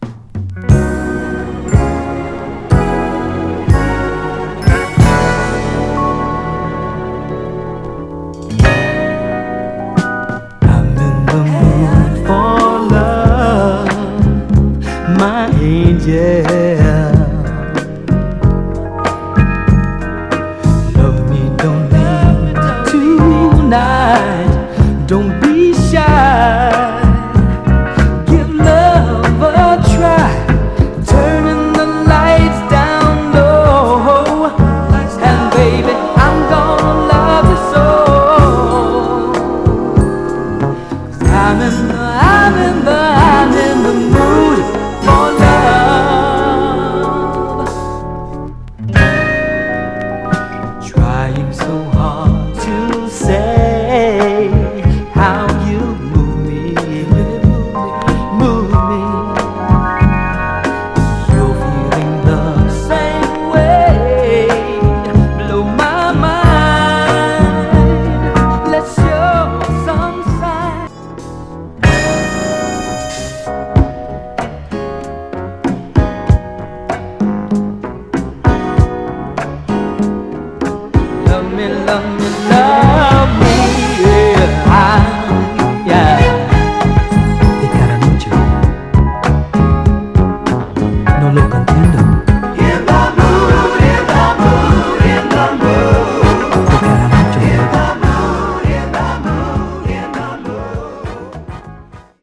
タフなヴァイナル・プレス、モノ/ステレオ収録のプロモ盤。
※試聴音源は実際にお送りする商品から録音したものです※